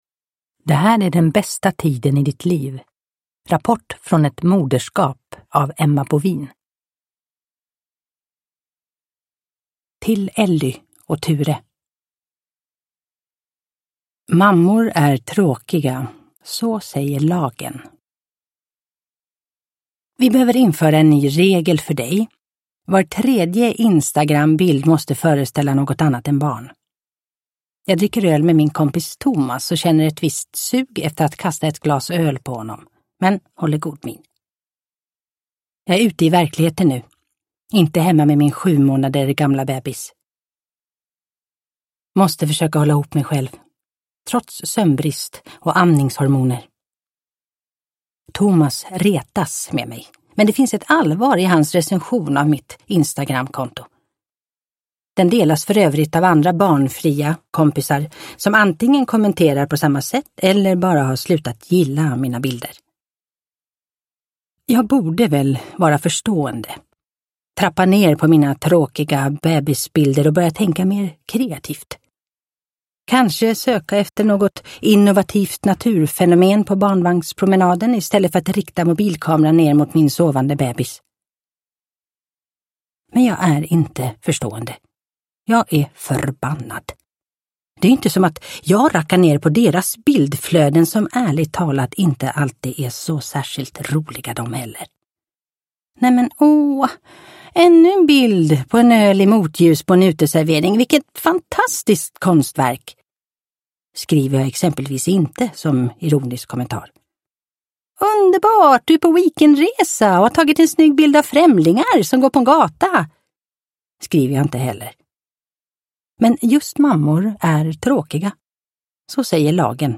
Det här är den bästa tiden i ditt liv : rapport från ett moderskap – Ljudbok – Laddas ner